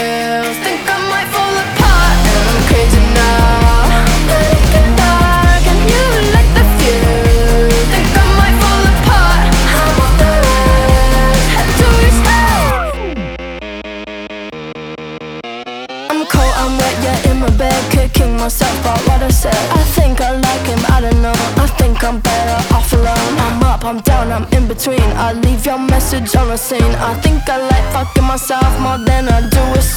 Скачать припев, мелодию нарезки
2025-06-20 Жанр: Альтернатива Длительность